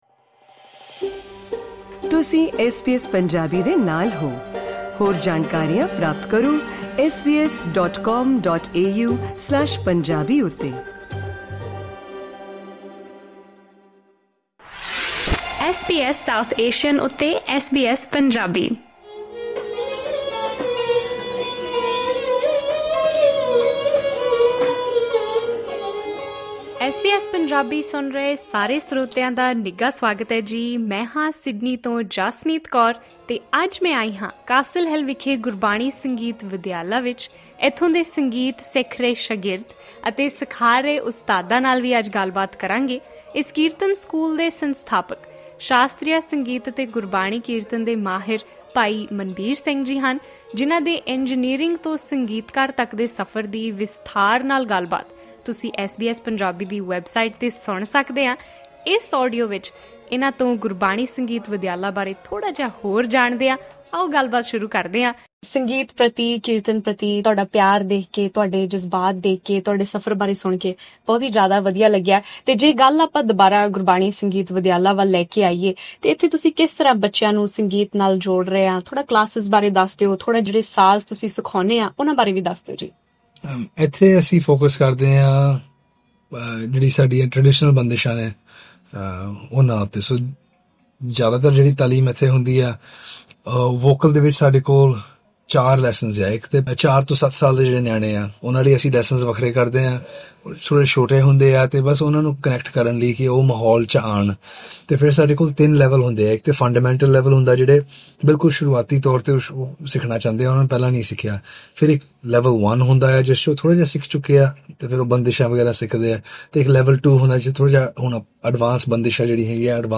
ਗੁਰਬਾਣੀ ਸੰਗੀਤ ਵਿਦਿਆਲਾ ਨਾਲ ਜੁੜੇ ਉਸਤਾਦ ਅਤੇ ਸ਼ਾਗਿਰਦ ਐਸ ਬੀ ਐਸ ਪੰਜਾਬੀ ਨਾਲ ਗੱਲ ਕਰਦੇ ਹੋਏ ਸੰਗੀਤ ਪ੍ਰਤੀ ਆਪਣੇ ਜਜ਼ਬੇ ਨੂੰ ਬਿਆਨ ਕਰਦੇ ਹਨ।